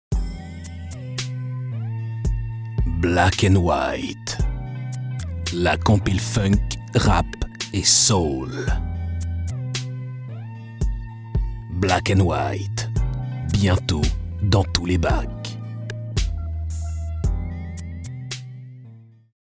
Couleur de la voix : Voix chaude et profonde pour narration ; posée, grave et naturelle pour documentaire, institutionnel, e-learning, corporate, voice over, audioguide ; cool, dynamique, sexy ou joué pour pub.
Sprecher französisch für Hörbücher, Dokumentation e-learning Werbung -
Sprechprobe: Werbung (Muttersprache):
french voice over artist: documentaire, institutionnel, e-learning, corporate, voice over, audioguide